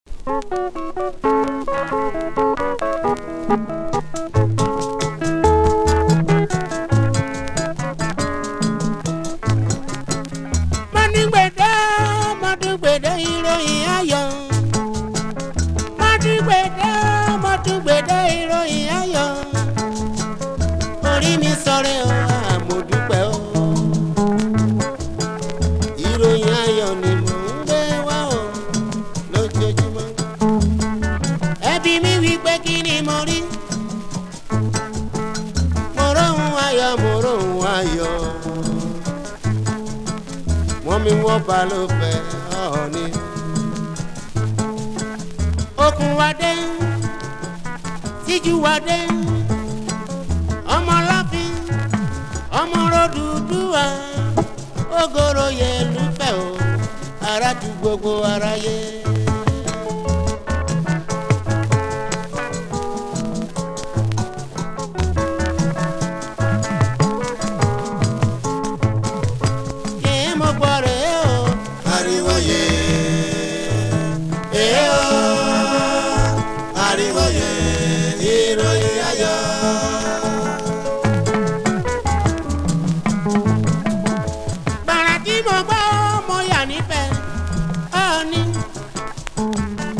両面共にゆるめなアーシーな質感が心地良いです。
# AFRO